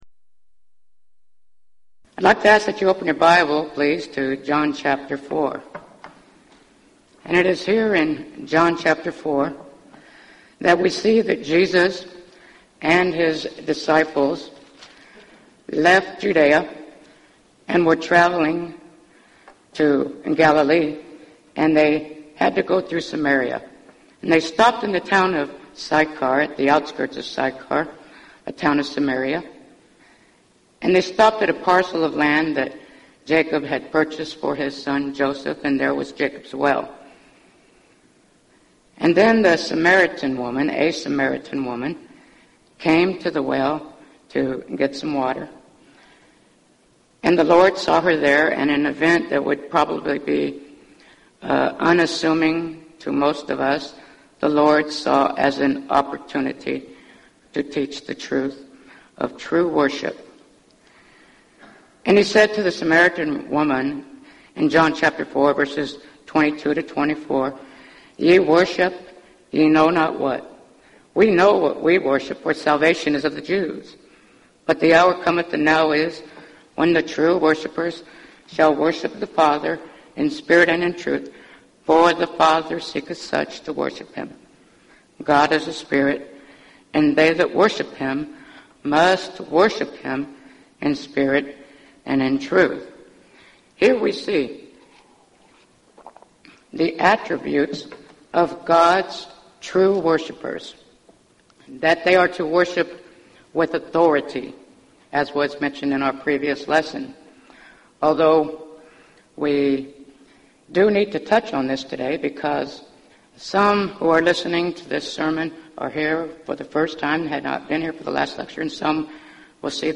Event: 1999 Gulf Coast Lectures
lecture